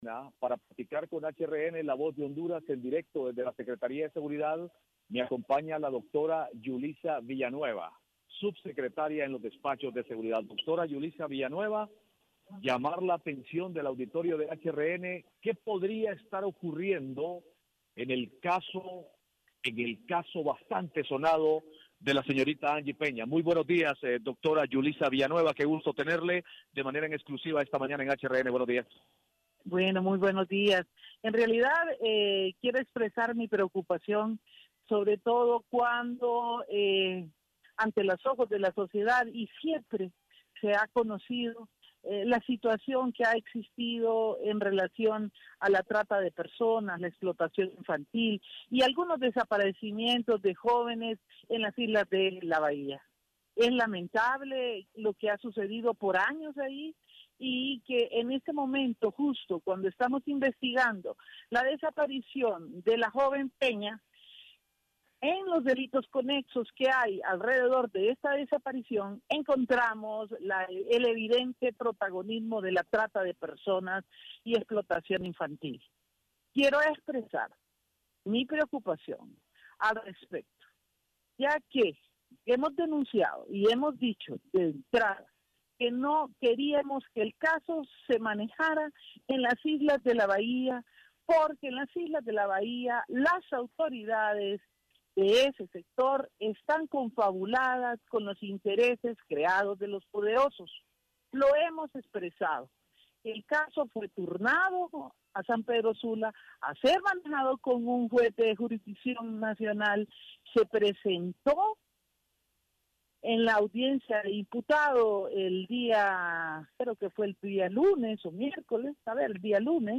En vivo - HRN Radio